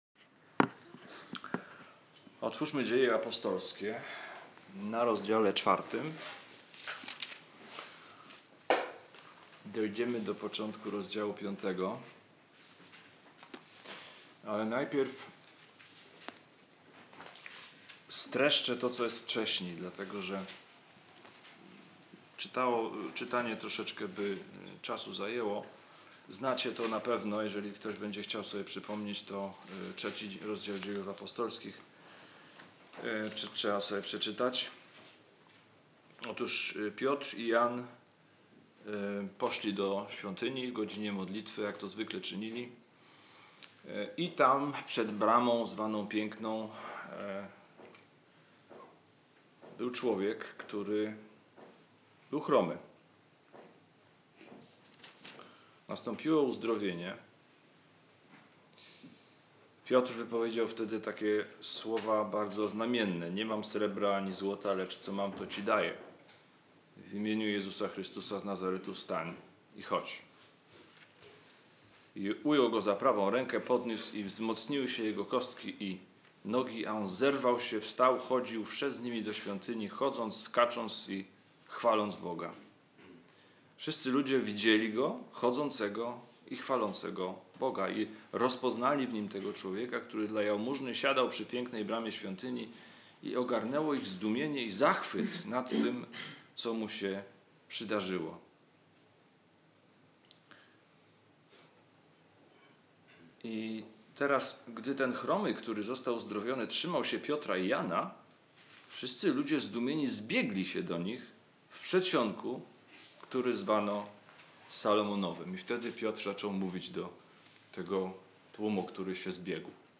Ulica Prosta - Kazania z 2018